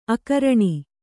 ♪ akaraṇi